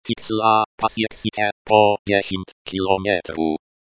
Pažiūrėjau, kad eSpeak TTS variklis palaiko lietuvių kalbą.